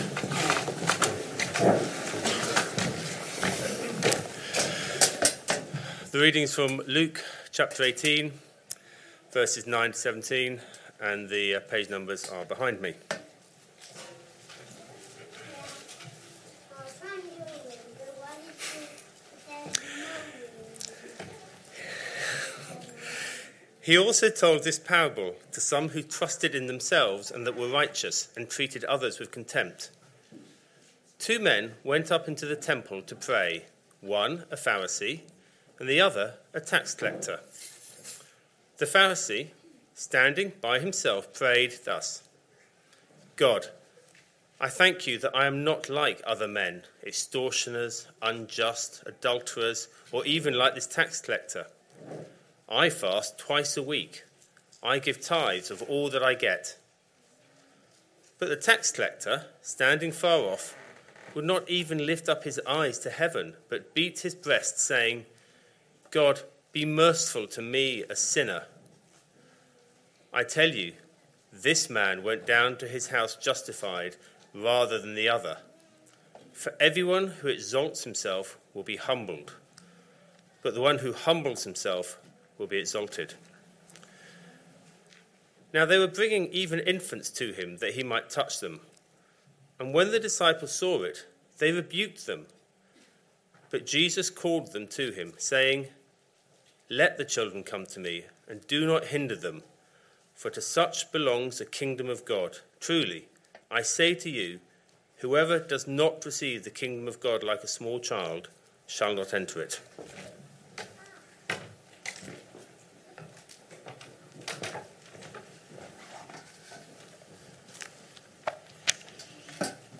Sunday AM Service Sunday 2nd November 2025 Speaker